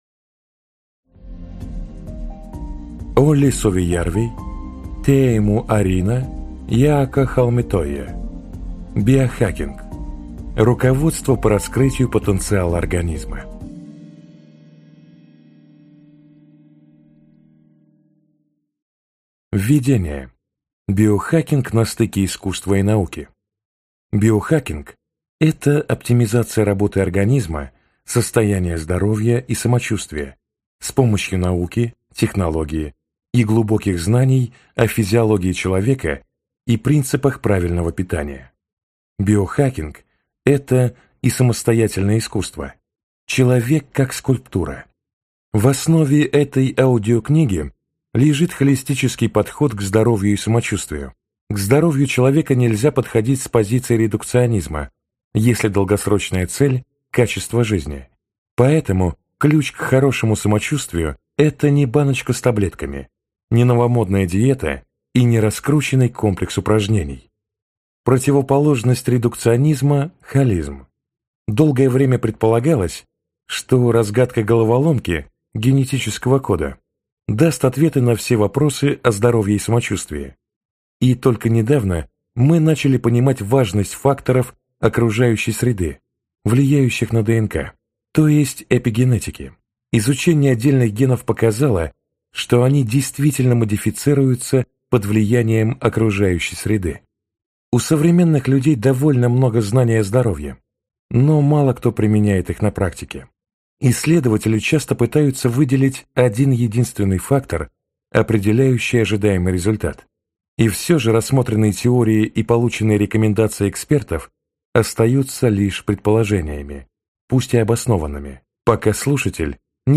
Аудиокнига Биохакинг | Библиотека аудиокниг